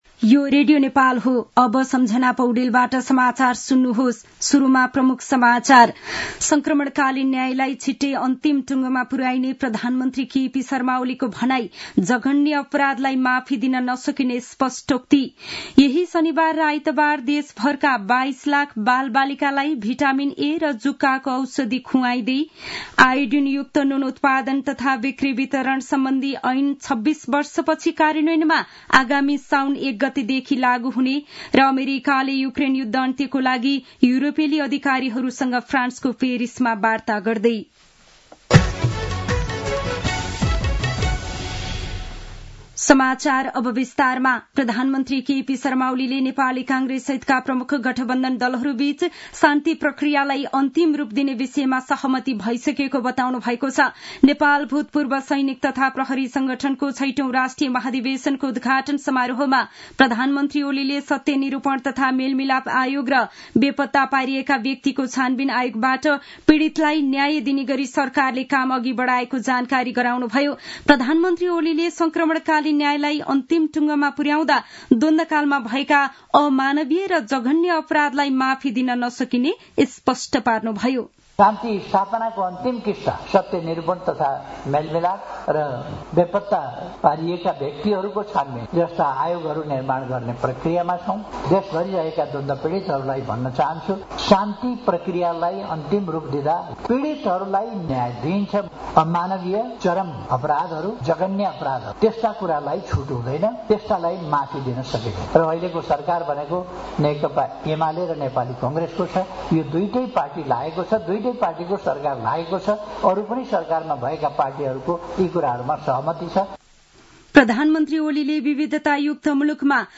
दिउँसो ३ बजेको नेपाली समाचार : ४ वैशाख , २०८२